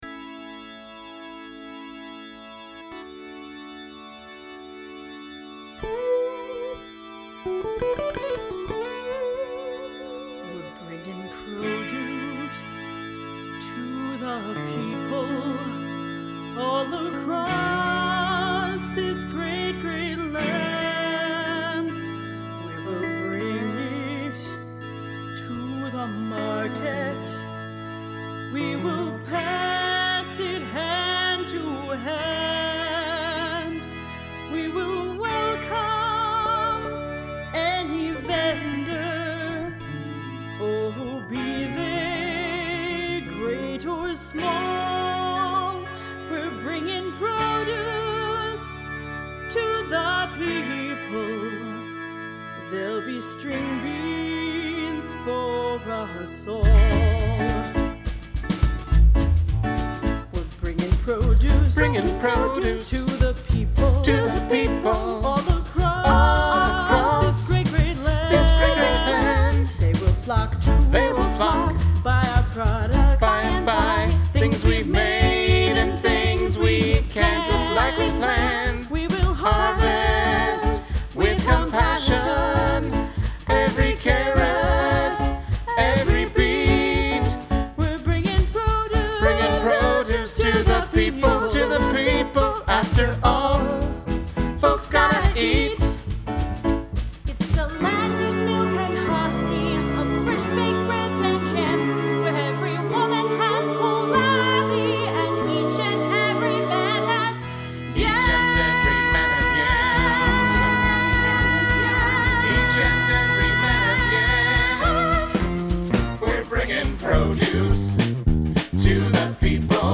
fun and comical up-tempo gospel number